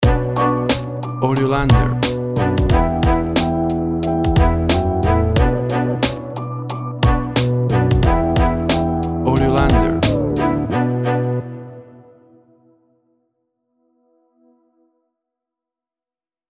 WAV Sample Rate 16-Bit Stereo, 44.1 kHz
Tempo (BPM) 90